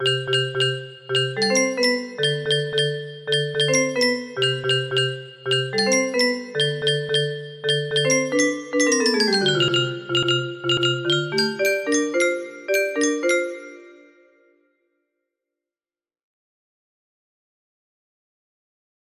I'll finish it later music box melody
Yay! It looks like this melody can be played offline on a 30 note paper strip music box!